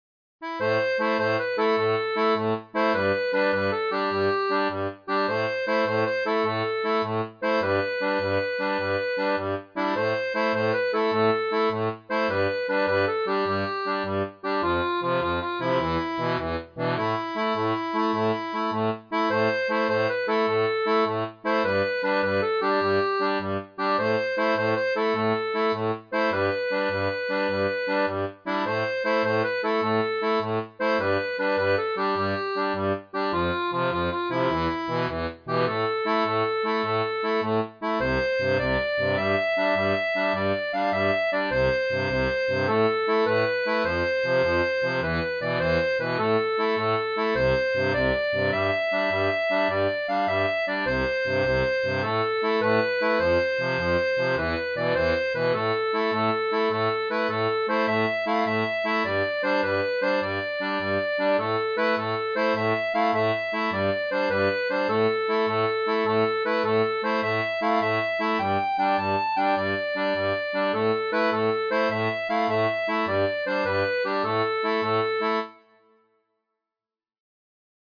• Une tablature pour diato à 2 rangs
Chant de marins